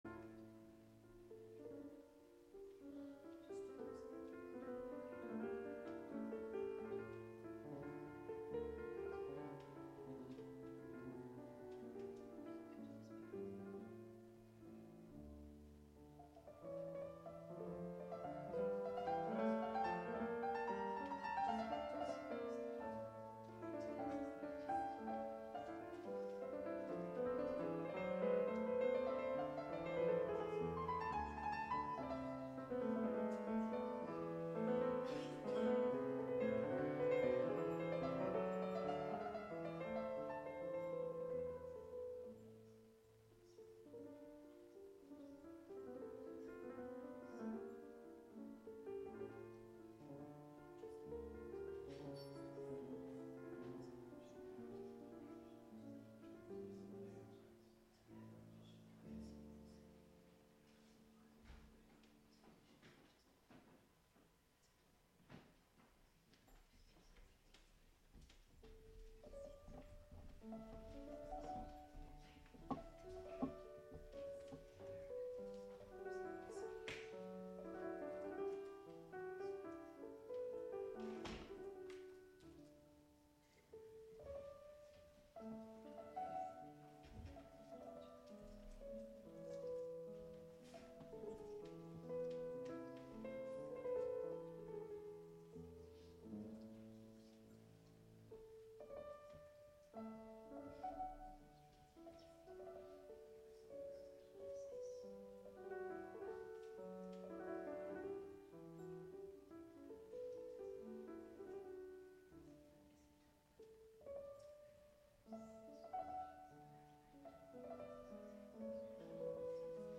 Live from The Flow Chart Foundation